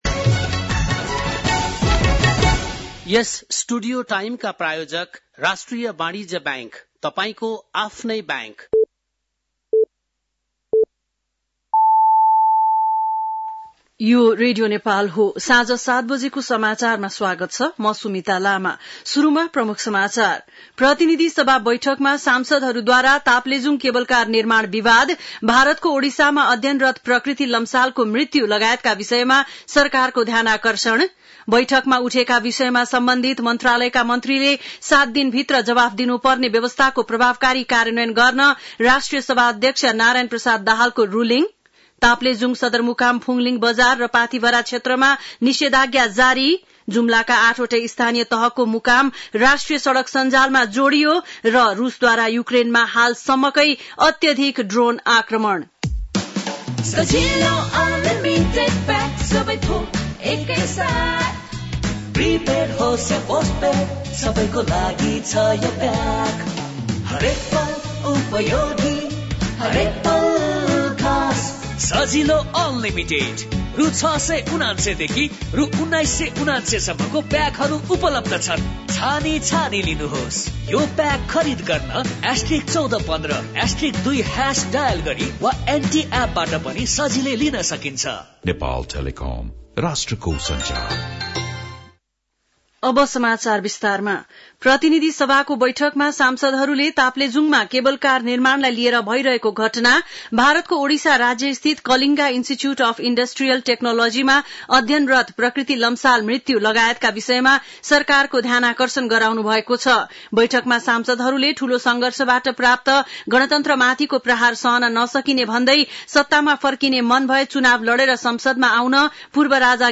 बेलुकी ७ बजेको नेपाली समाचार : १२ फागुन , २०८१
7-pm-nepali-news-11-11.mp3